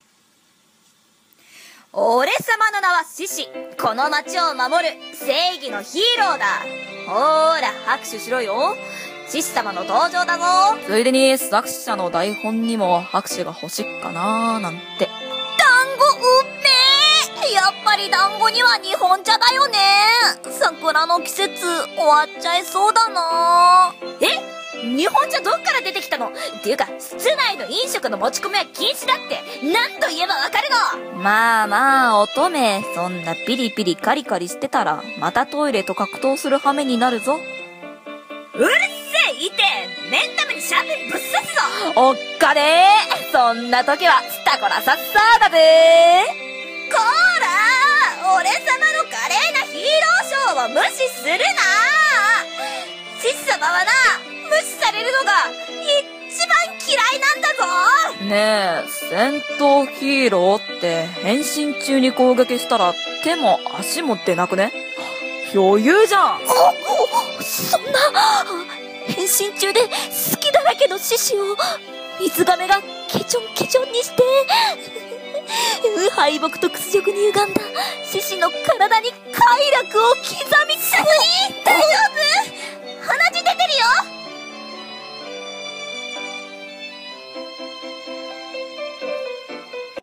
【1人7役声劇】12星座は今日も平常運転 1/2